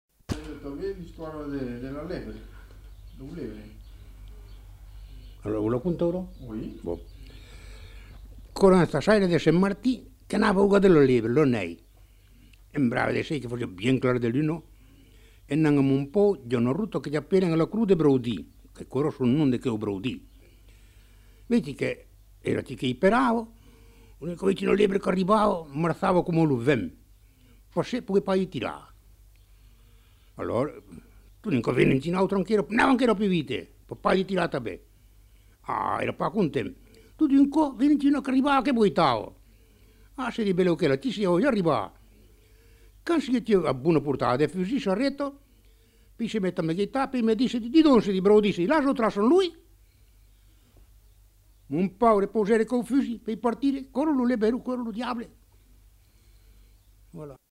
Lieu : Saint-Rémy-de-Gurson
Genre : conte-légende-récit
Effectif : 1
Type de voix : voix d'homme
Production du son : parlé